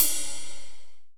D2 RIDE-07-R.wav